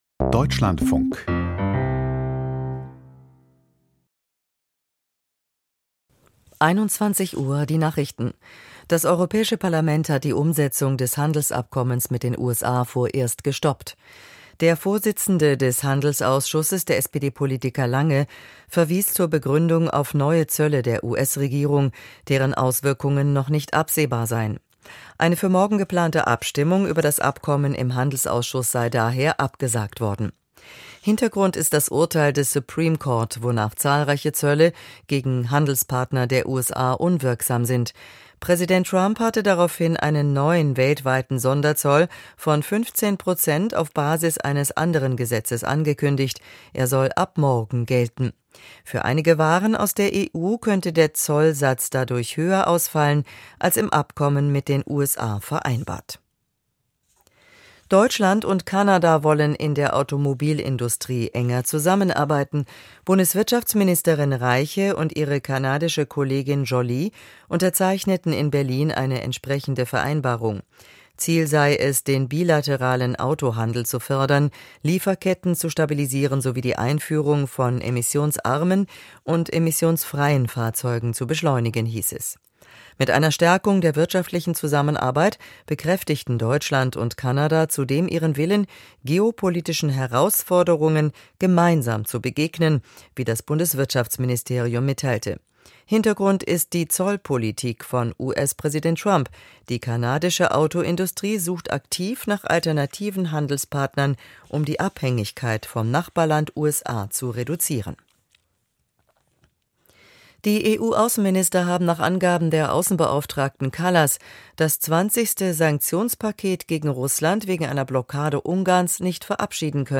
Die Nachrichten vom 23.02.2026, 20:59 Uhr